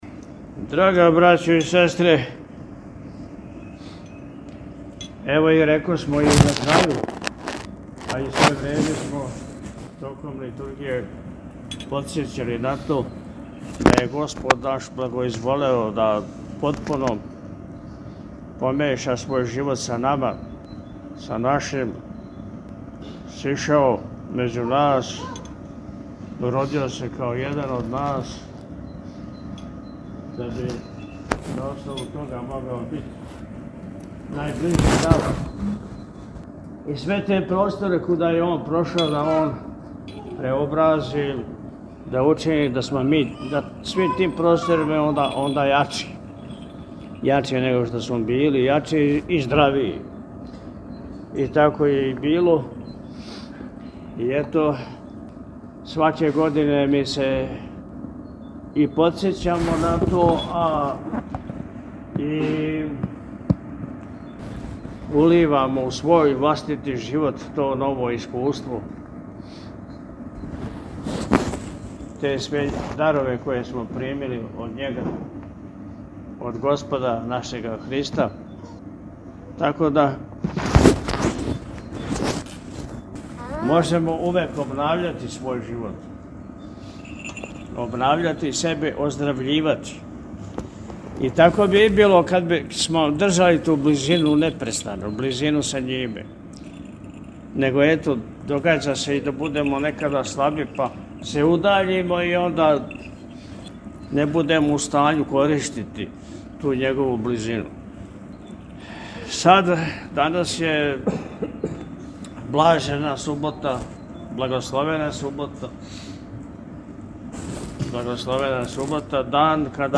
Бања-Беседа.mp3